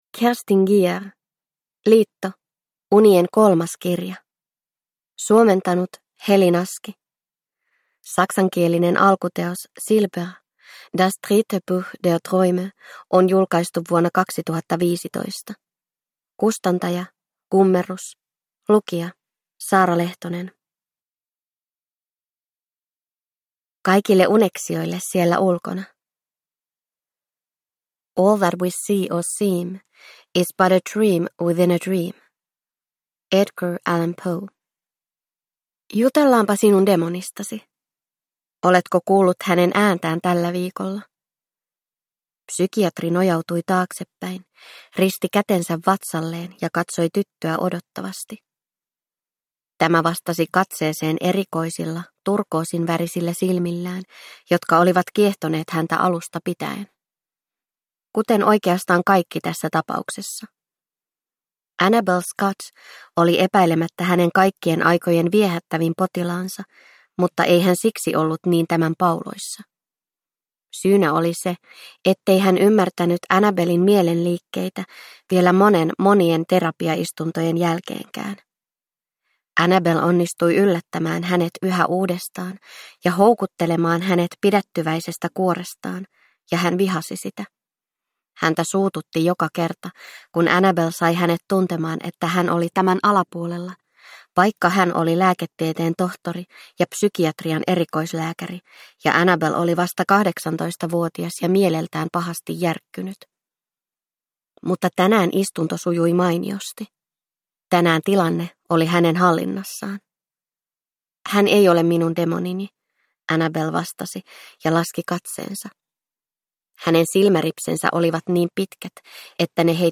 Liitto - Unien kolmas kirja – Ljudbok – Laddas ner